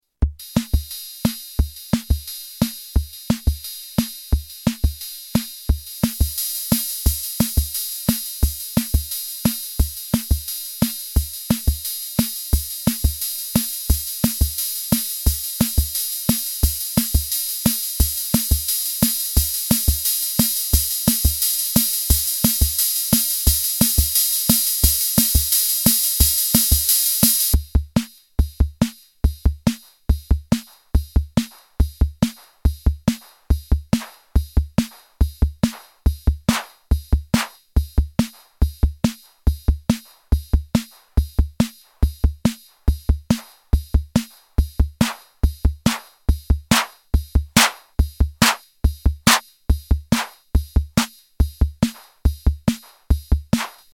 The Korg's commercial response to the portable Roland TR-606: an analog drum machine with programming and sync options.
button kick button snare button open/close hh
button claps button cymbal button tom (x2)
drum pattern demo
REVIEW "The sounds are really nice, really synthetic and metallic, but the painful edit mode that can destroy any good willings"